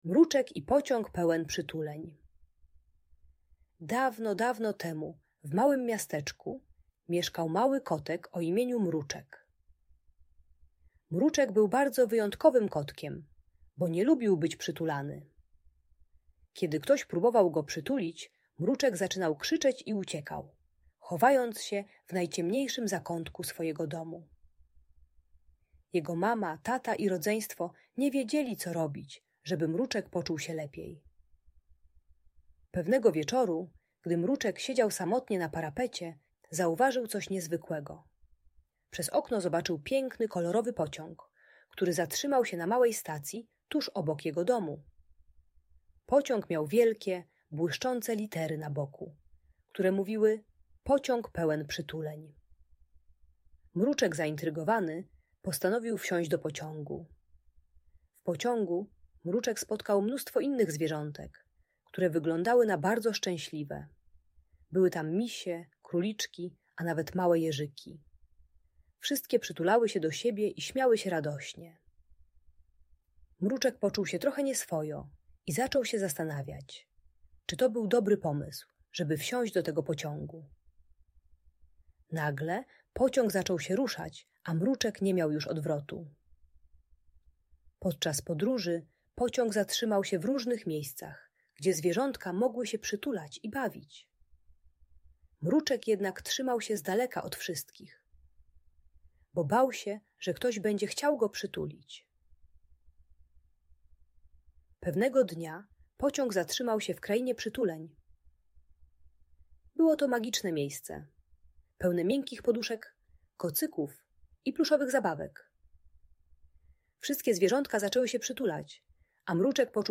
Historia o Kotku Mruczku i Pociągu Pełnym Przytuleń - Audiobajka